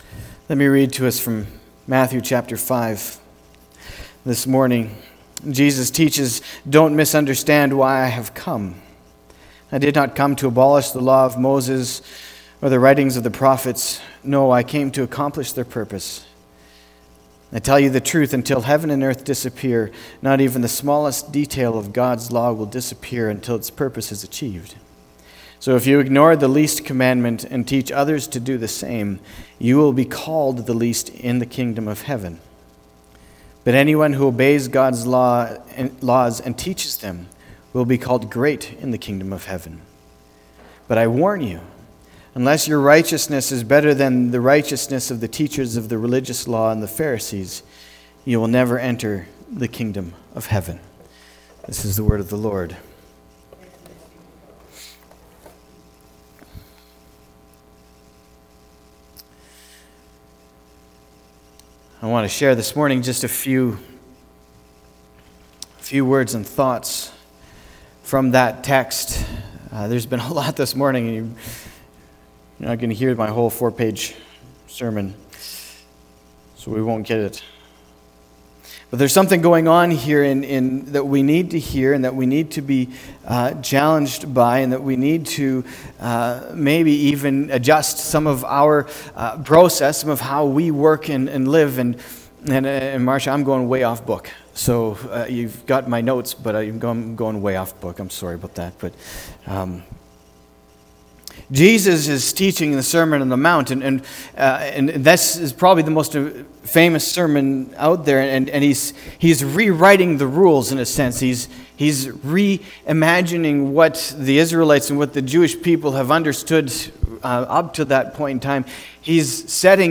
february-9-2020-sermon.mp3